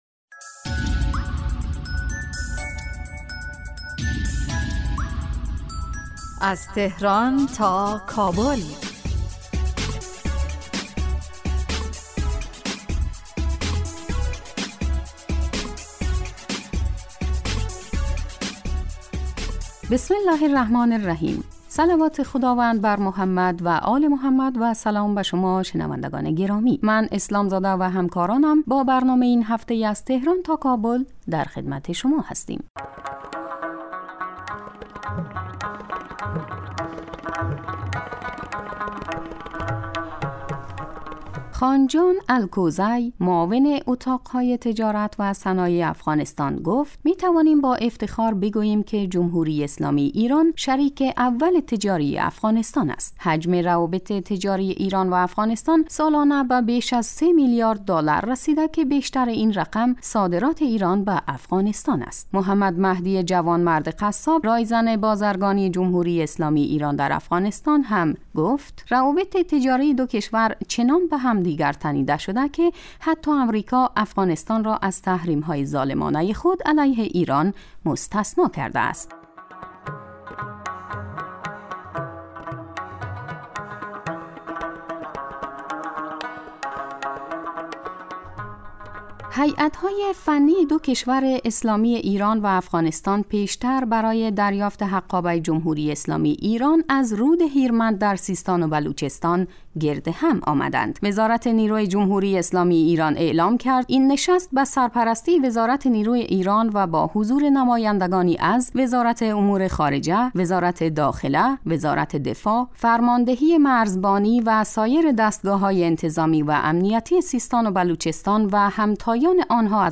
برنامه از تهران تا کابل به مدت 15 دقیقه روز جمعه در ساعت 11:30 ظهر (به وقت افغانستان) پخش می شود. این برنامه به رویدادهای سیاسی، فرهنگی، اقتصادی و اجتماعی مشترک ایران و افغانستان می پردازد.